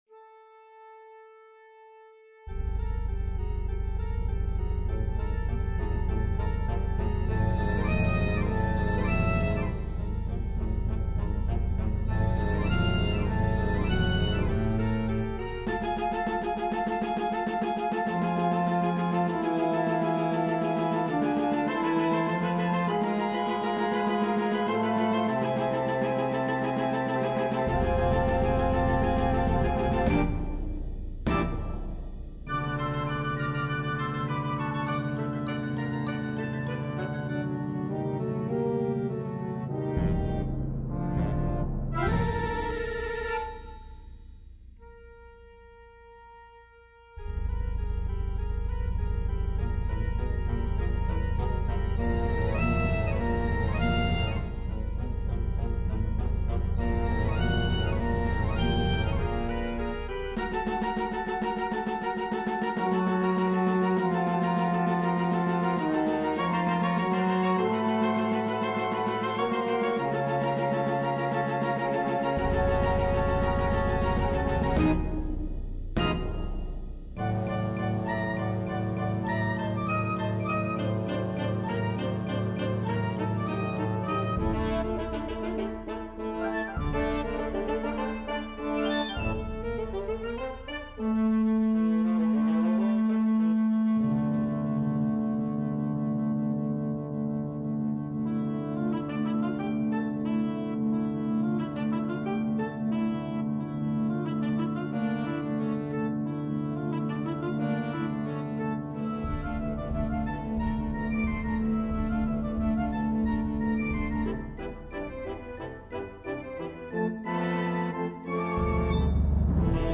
Der Tanz von Hexen wurde nie besser in Musik dargestellt.